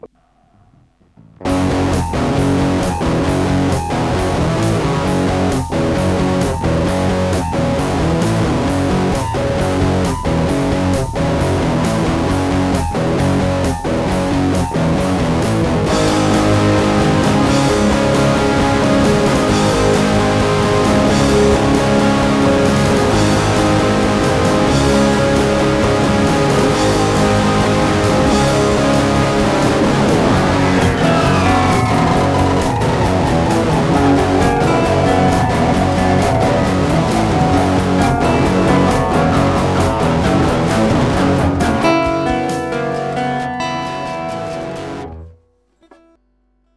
About a decade ago, I was dicking around with some recording gear and sketched out a song.
Just the chord progression or is it that grungy as well?
It was distorted guitar, but not quite that grungy.